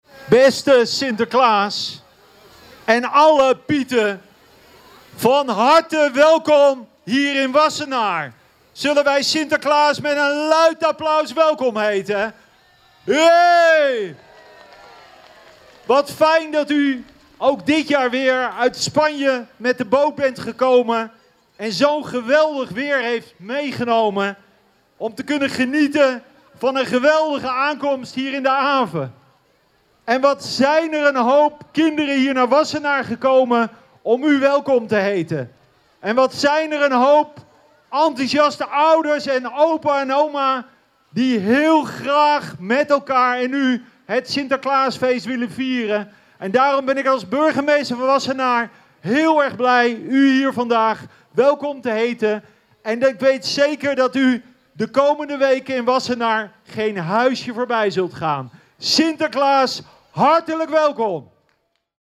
Even later zette de Goedheiligman voet aan wal, waar hij werd verwelkomd door burgemeester Leendert de Lange.
De burgemeester verwelkomt Sinterklaas.
Burgemeester-Leendert-de-Lange-verwelkomt-Sinterklaas.mp3